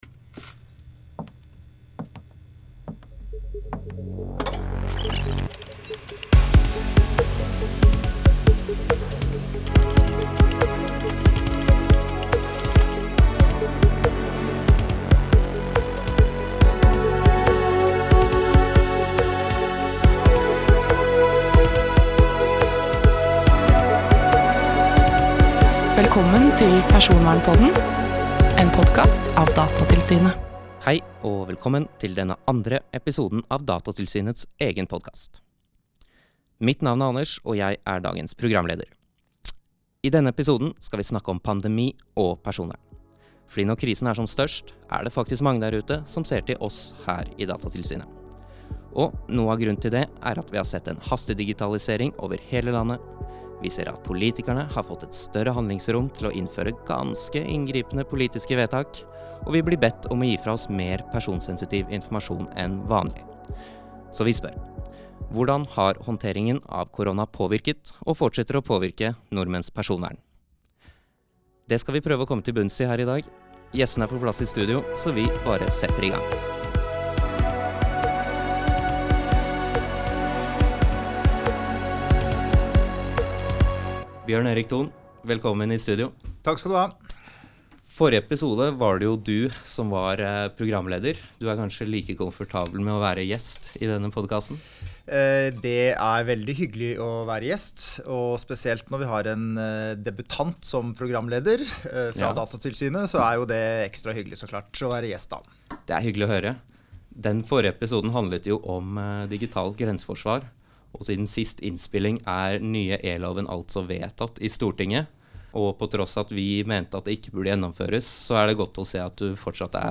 Vi tar debatten i den andre episoden av Datatilsynets nye podkast.